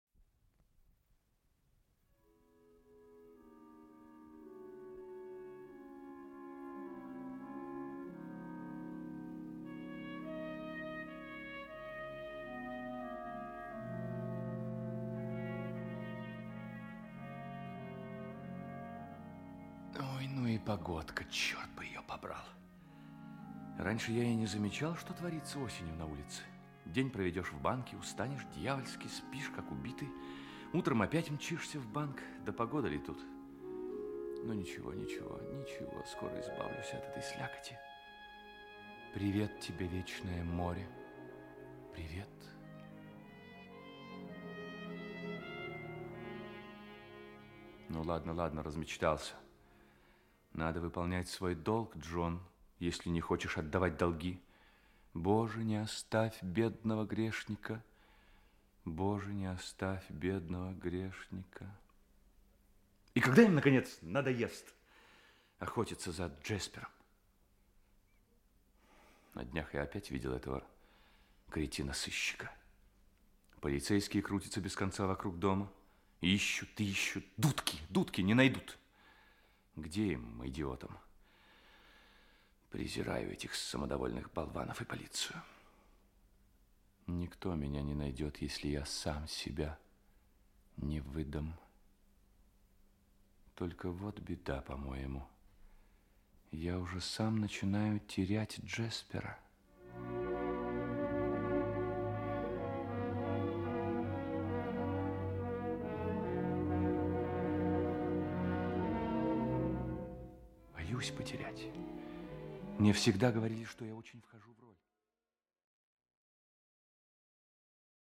Аудиокнига Ивовая аллея. Часть 2 | Библиотека аудиокниг
Часть 2 Автор Льюис Синклер Читает аудиокнигу Актерский коллектив.